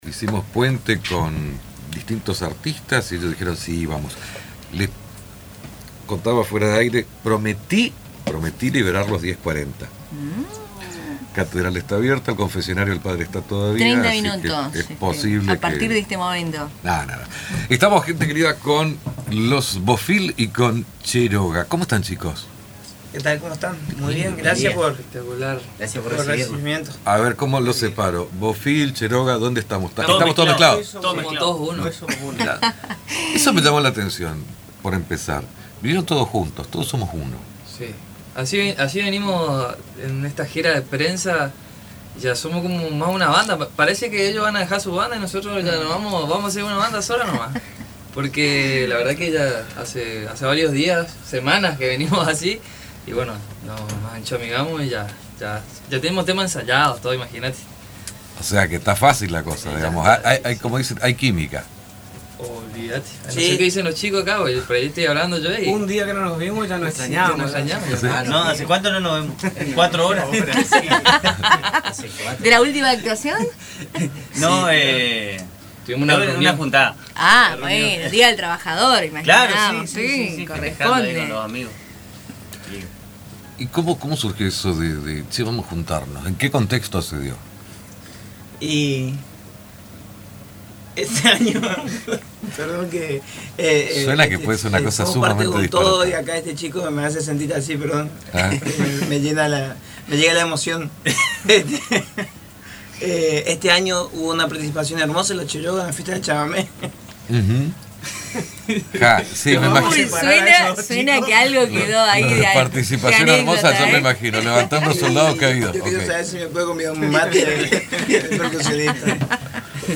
En su visita a Radio Tupa Mbae